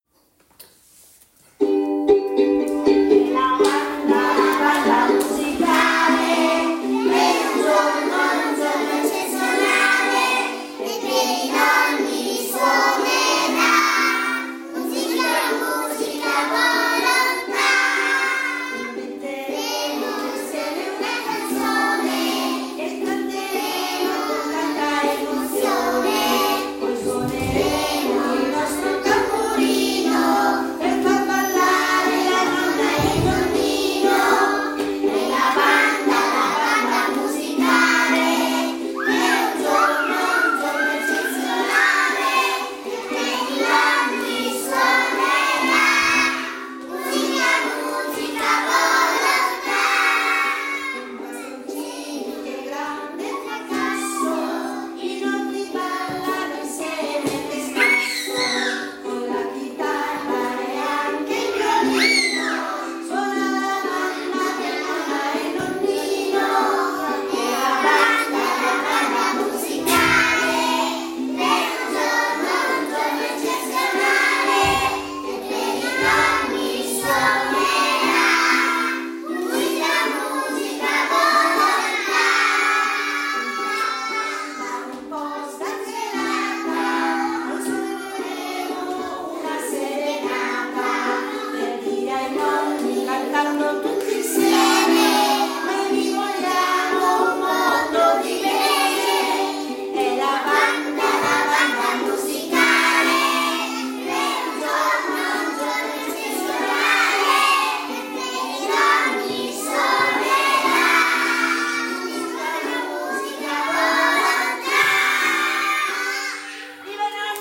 2 OTTOBRE: LA FESTA DEI NONNI. GLI AUGURI DALLA SCUOLA DELL’INFANZIA DI CENCENIGHE
canzone-infanzia.mp3